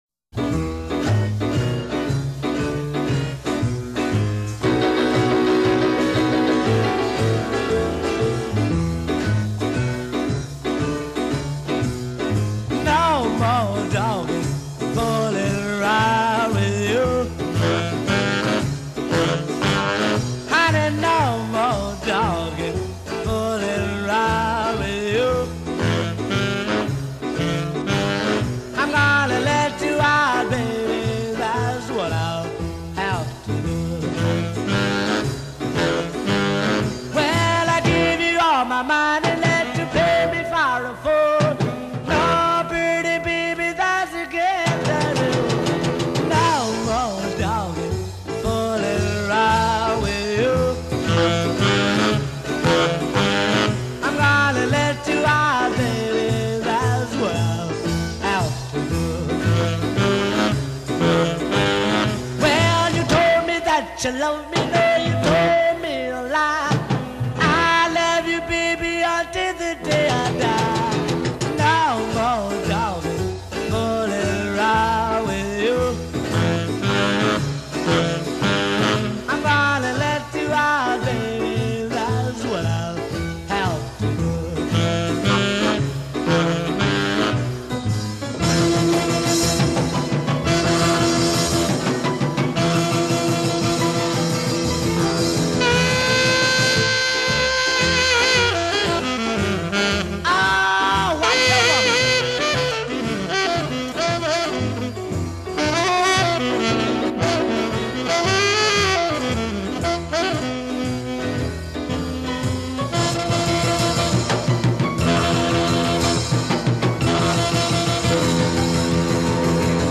R&B
back-beat style